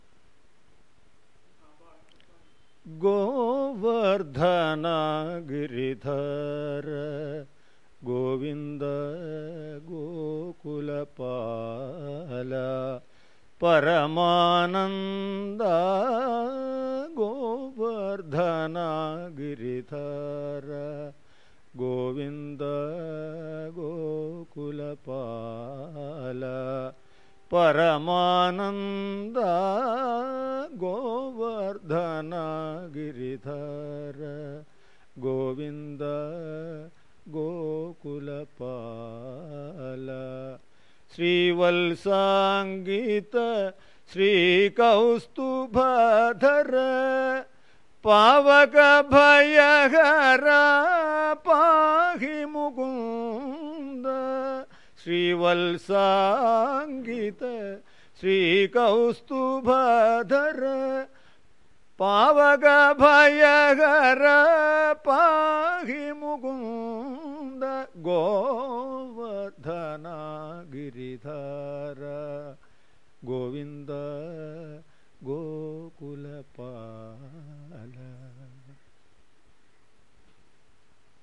音乐课 " 印度音乐学校
描述：来自印度音乐学院，喀拉拉邦。
Tag: 卡纳提克 compmusic 音乐 gamaka 我NDIA 印度斯坦